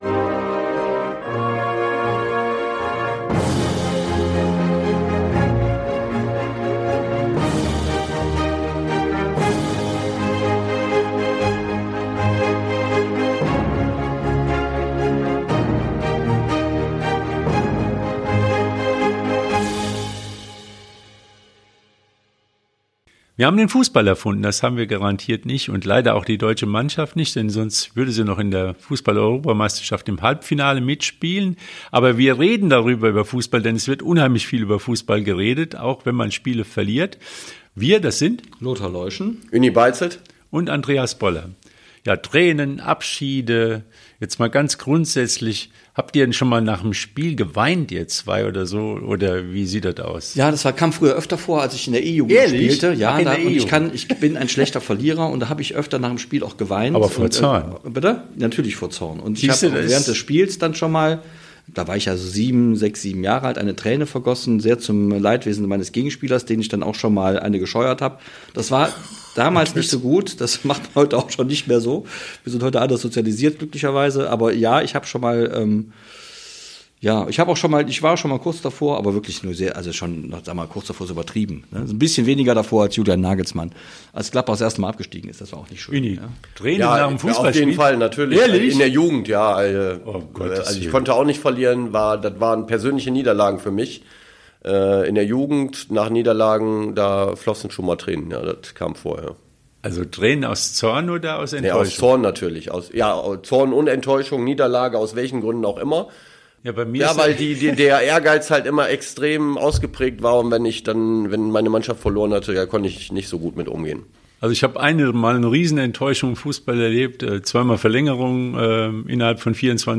Beschreibung vor 1 Jahr Auf ein Neues heißt es für die Nationalmannschaft nach dem EM-Aus und für die Lokalmatadoren vom Wuppertaler SV nach dem verpassten Aufstieg. Eine hitzige Debatte liefert sich das Podcast-Team über die Gründe der Niederlage gegen Spanien. Erste positive Eindrücke haben die Zugänge des WSV hinterlassen, aber auch auf der kleineren Fußballbühne deutet alles auf einen langwierigeren Neuaufbau mit vielen jüngeren Spielern hin. Mehr